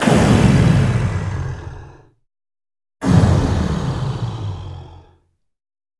Dragon Moan
creature creep dinosaur dragon enemy evil game-design growl sound effect free sound royalty free Gaming